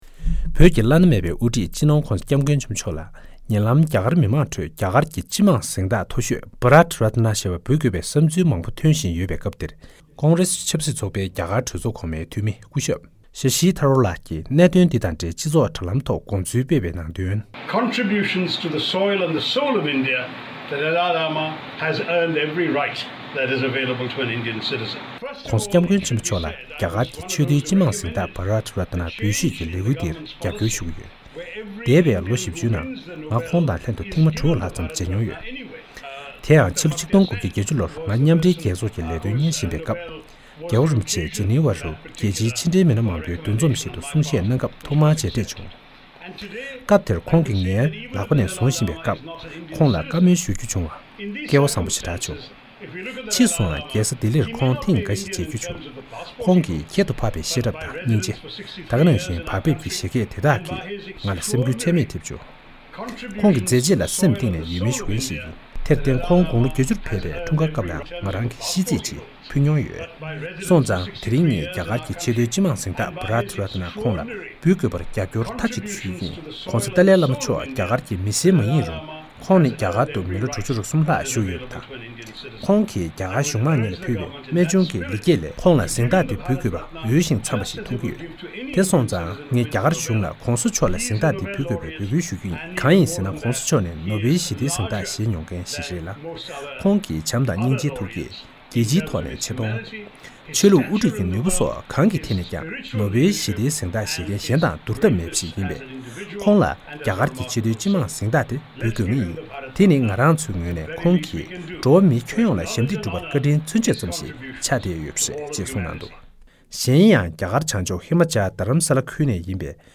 བཞུགས་སྒར་རྡ་རམ་ས་ལ་ནས་འདི་གའི་གསར་འགོད་པ